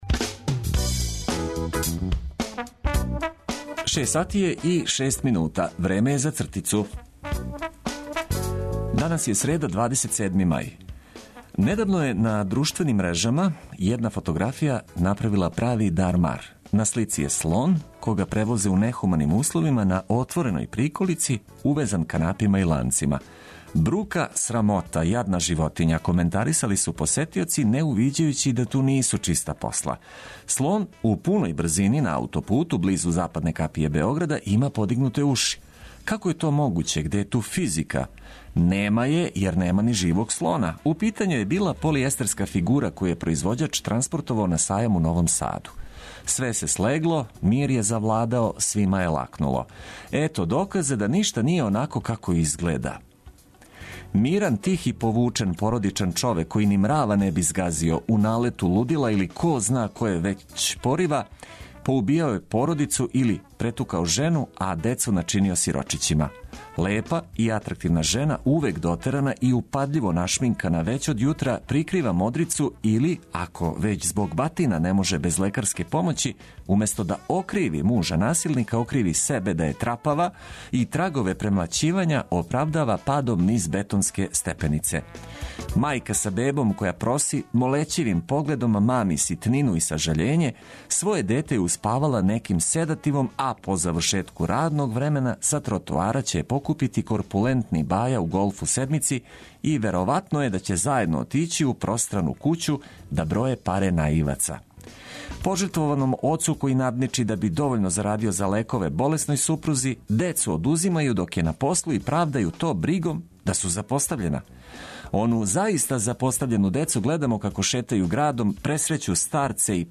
Нека ова среда почне уз добру музику и информације од којих ћете имати користи.
Уредник и водитељ